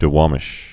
(də-wämĭsh) also Dwa·mish (dwämĭsh)